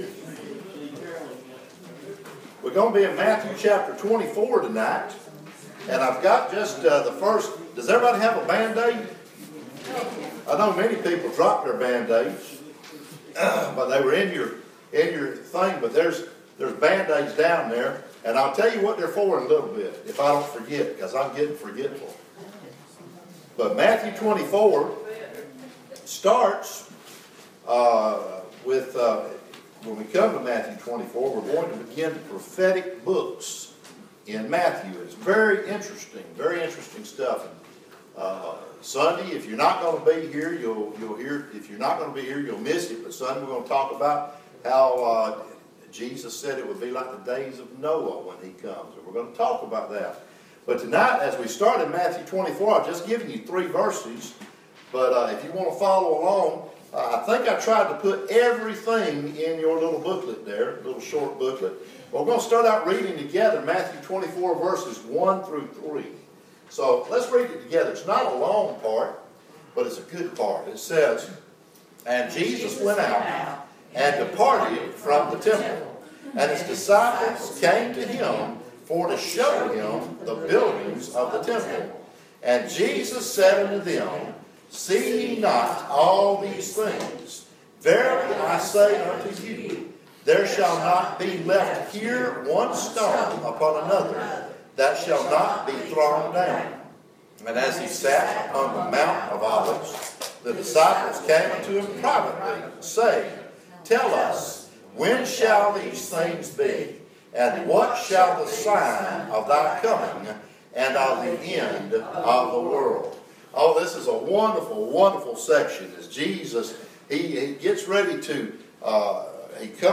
Preached at Riverview Baptist on Wednesday, July 19 following prayer meeting.
The sermon was preached without notes, Bible in hand, so the notes will not exactly follow what was preached.